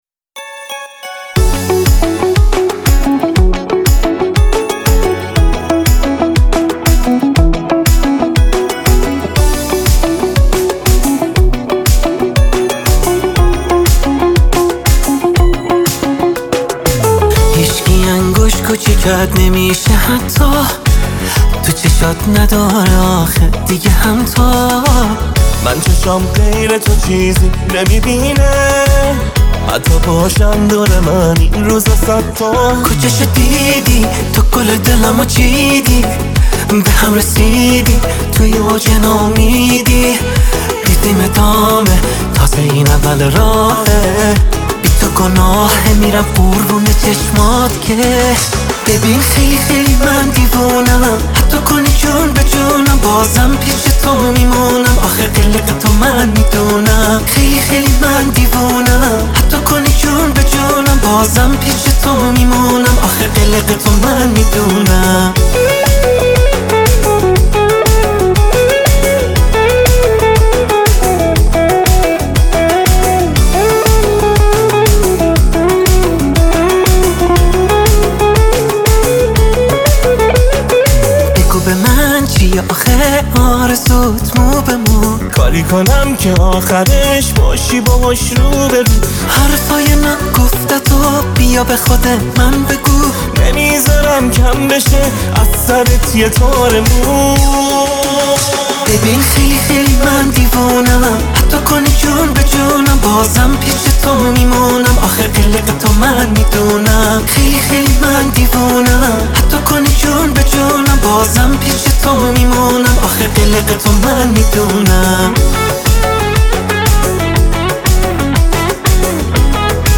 پاپ شاد